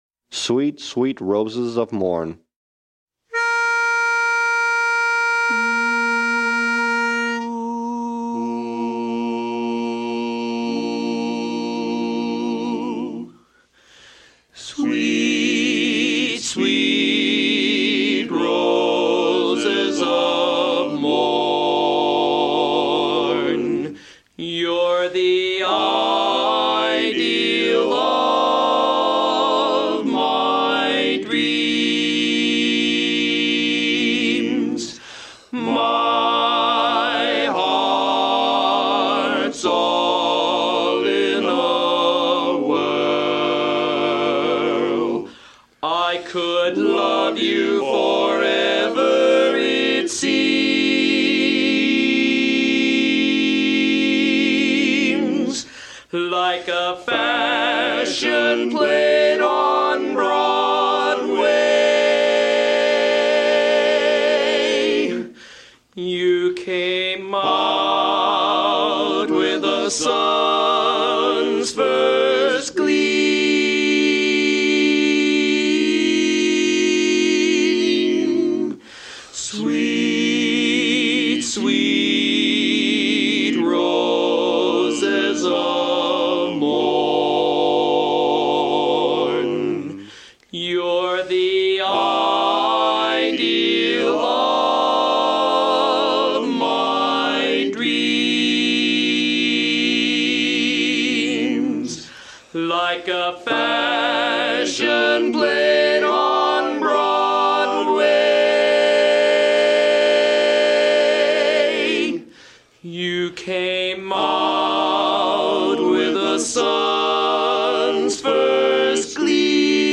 Von 19:15 bis 20:00 Uhr proben die NoNames, unser kleines, aber feines, Männerensemble, am gleichen Ort.
Sweet sweet Roses of Morn Lead